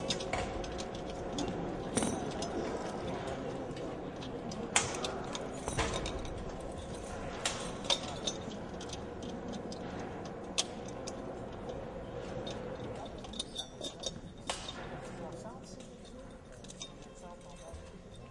随机 " 剧院工作人员的舞台设置 魁北克语和普通话的声音5